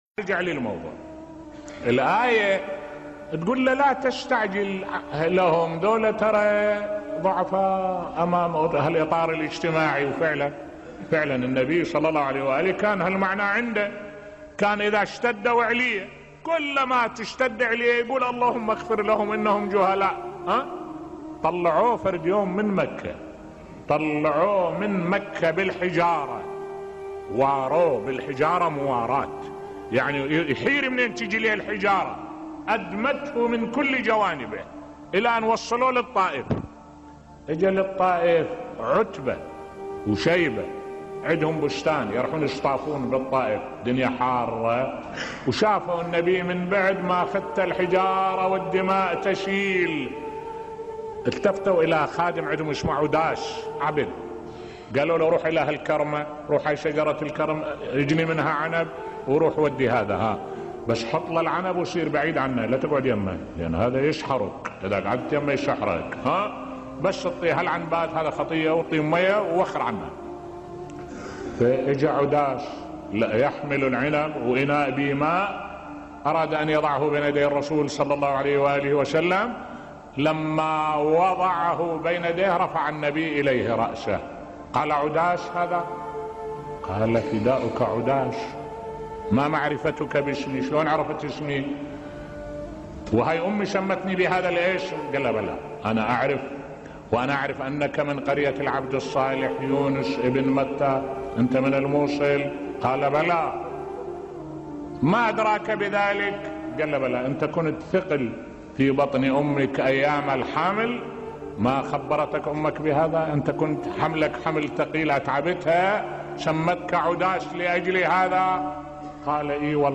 ملف صوتی رِفق النبي ( ص) بقومه بصوت الشيخ الدكتور أحمد الوائلي